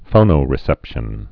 (fōnō-rĭ-sĕpshən)